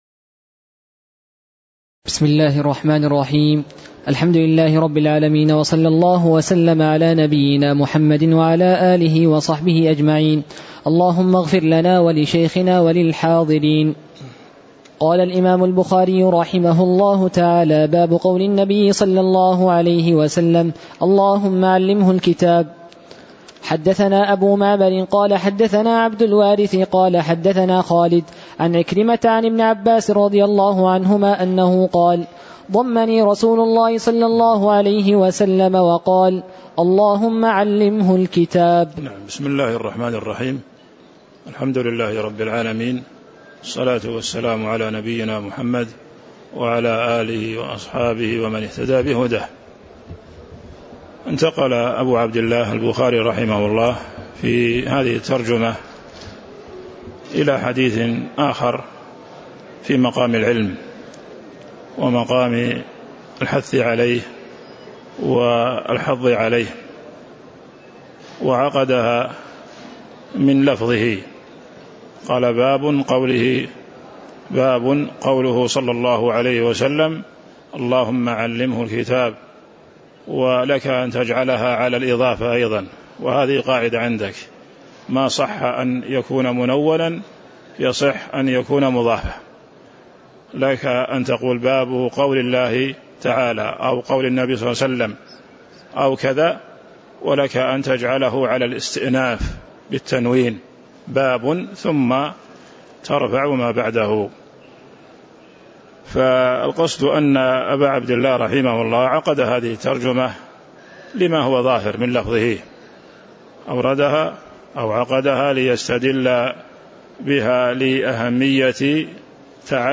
تاريخ النشر ١٢ ربيع الثاني ١٤٤٣ هـ المكان: المسجد النبوي الشيخ